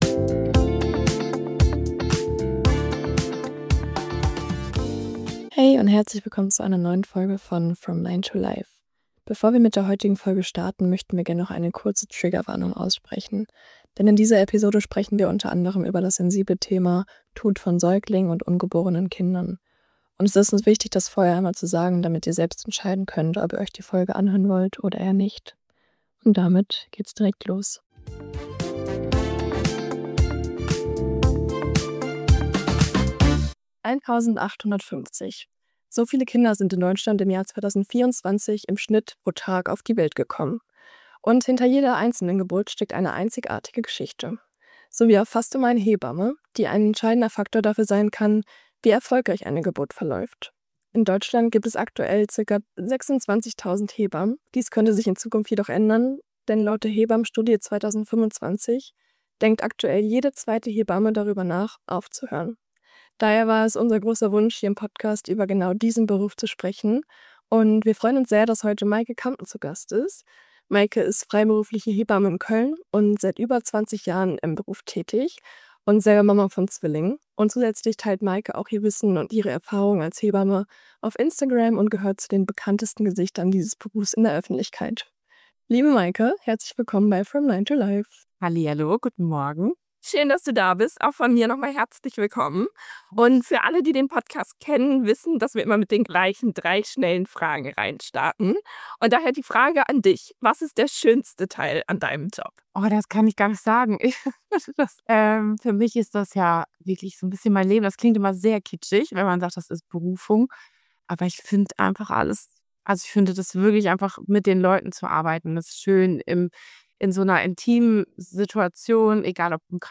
Ein ehrliches Gespräch über den Joballtag zwischen Berufung und Herausforderung.